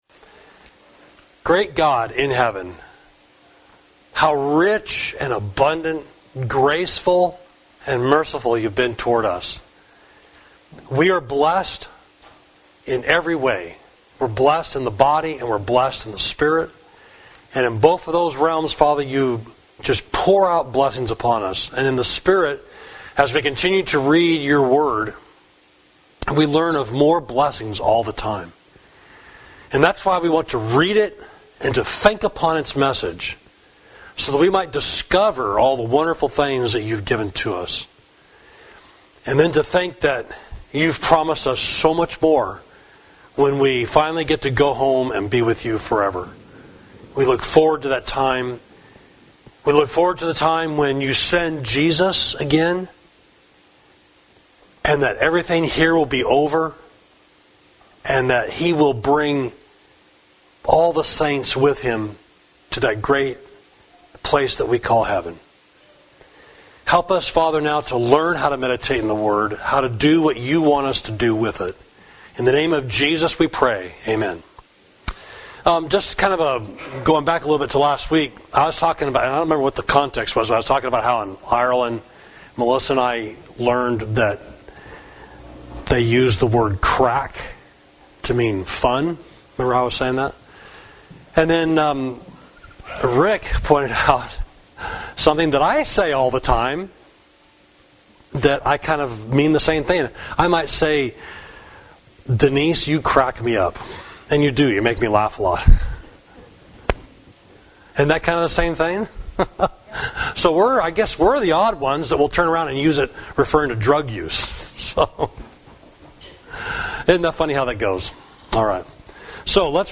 Class: The Pleasure of Meditation, Lesson 3